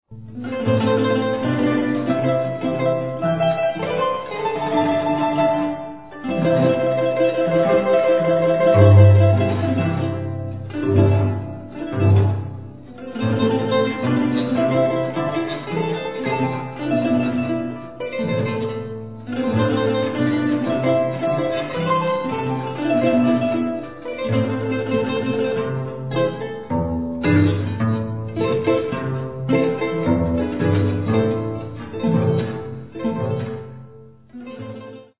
Classic Andean harp and violin music